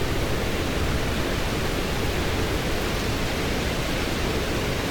wind.ogg